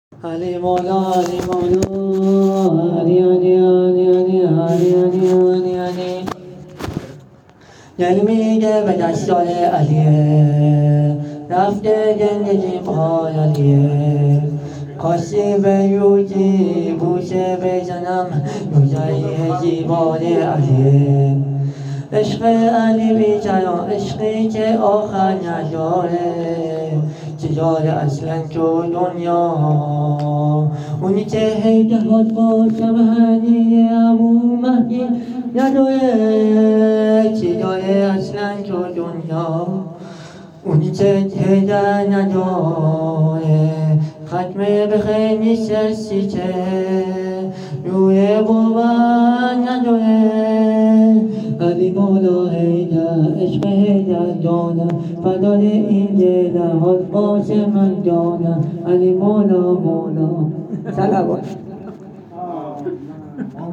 مداح اهل بیت
هیت ابافضل العباس امجدیه تهران